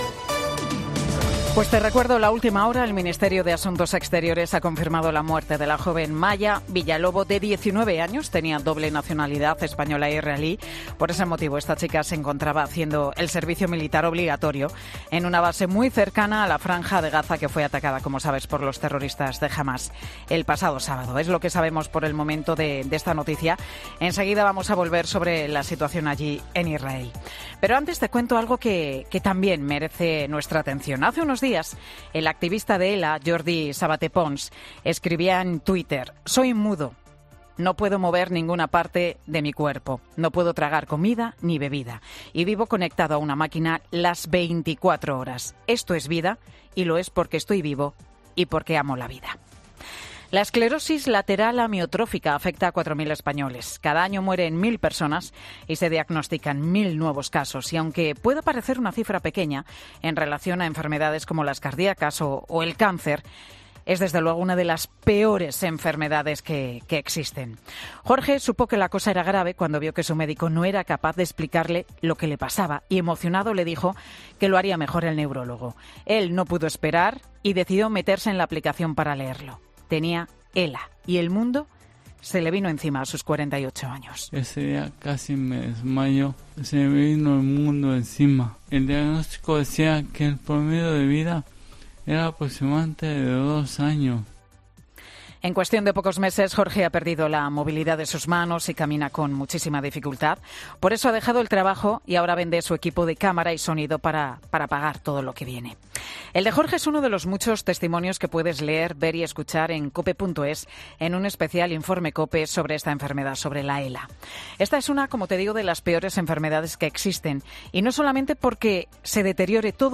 Monólogo de Pilar García Muñiz Pilar García Muñiz: "Urge una ley ELA.